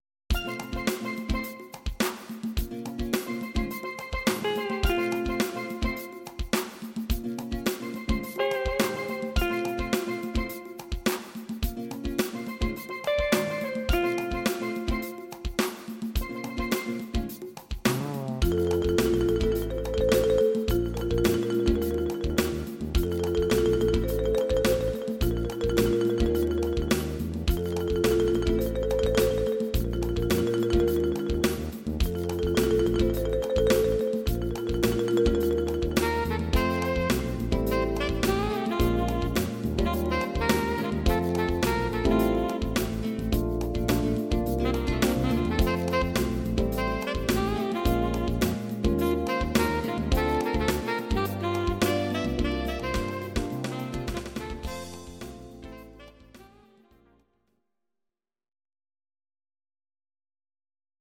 Audio Recordings based on Midi-files
Pop, Jazz/Big Band, Instrumental, 1970s